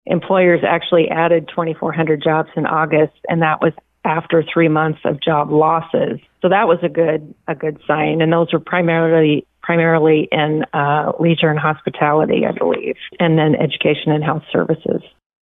Townsend says there were gains in some areas.